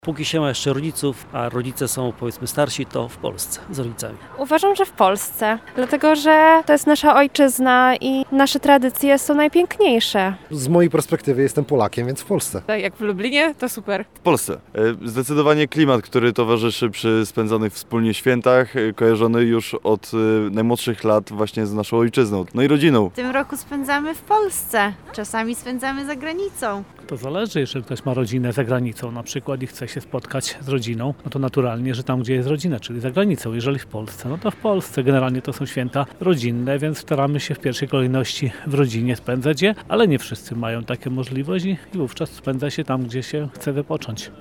[SONDA] Święta w domu czy w podróży?
Potwierdzają to mieszkańcy Lublina: